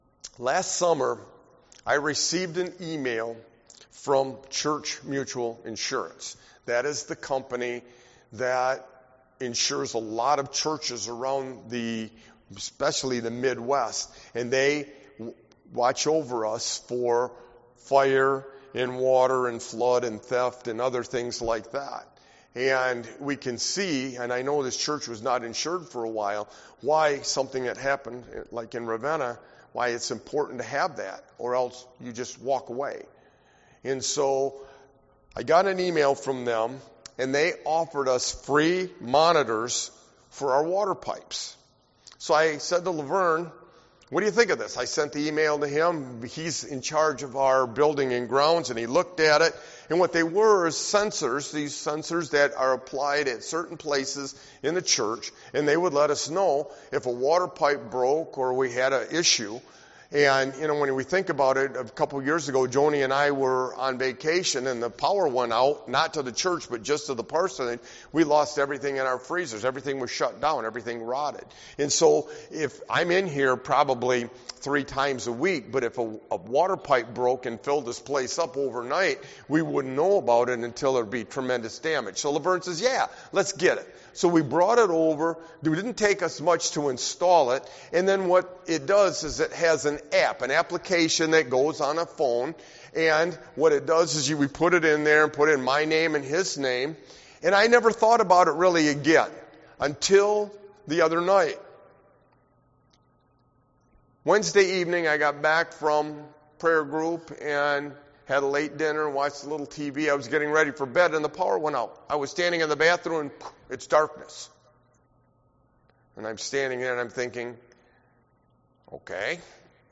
Sermon-Why-a-Sovereign-God-is-crucial-XI-4322.mp3